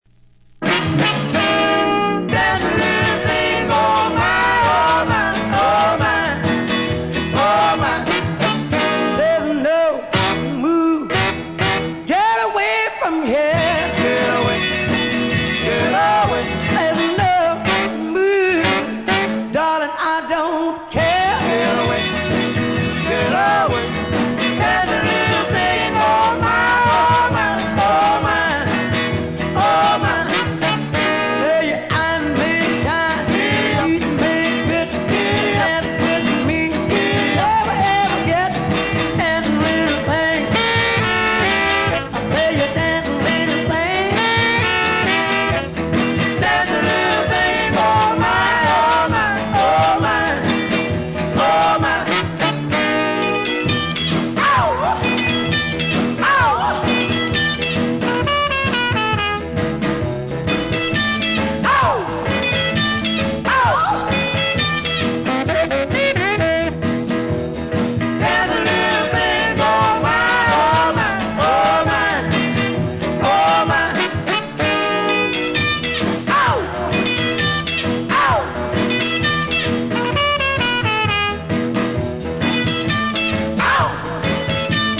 Genre: RARE SOUL